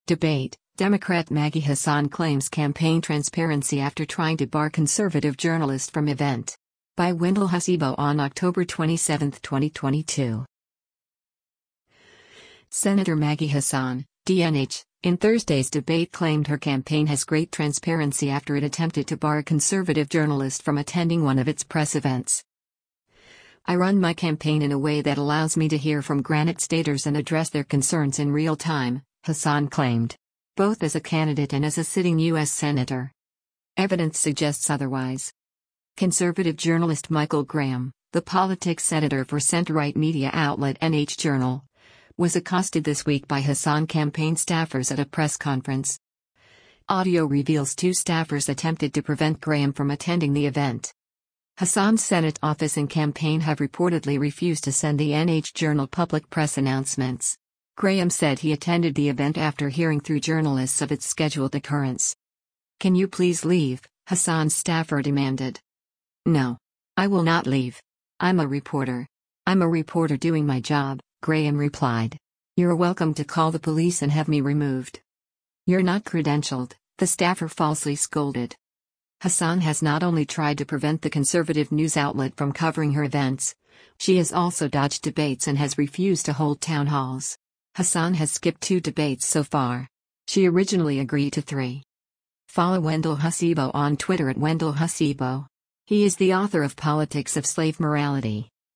Sen. Maggie Hassan (D-NH) in Thursday’s debate claimed her campaign has great transparency after it attempted to bar a conservative journalist from attending one of its press events.